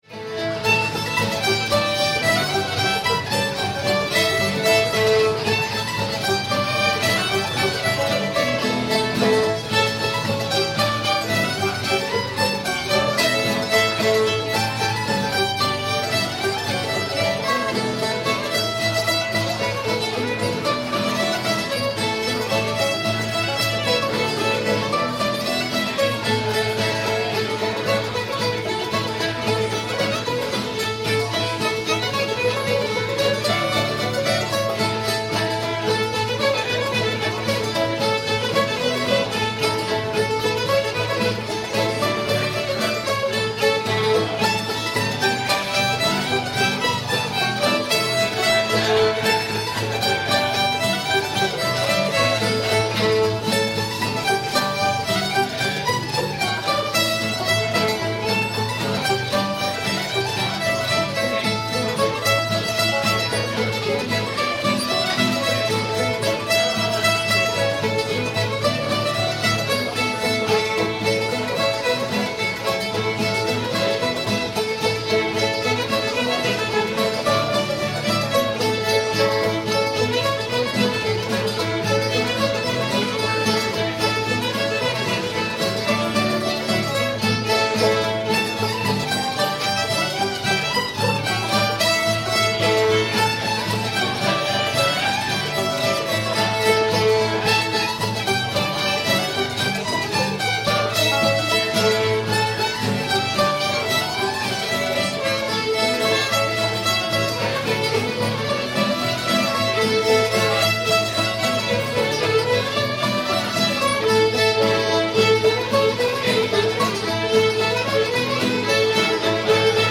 ways of the world [D]